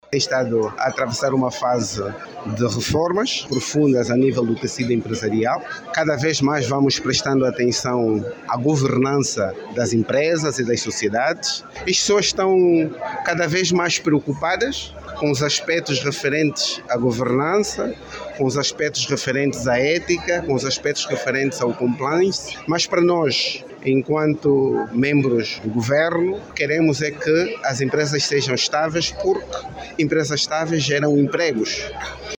Pedro José Filipe diz que a ideia é construir a boa governação e a estabilidade das empresas públicas e privadas, em nome da garantia da empregabilidade. O Secretário de Estado Pedro José Filipe, que falava no Primeiro Fórum Direito e Economia, sinalizou também o compromisso que se assiste das empresas pela ética e o compliance.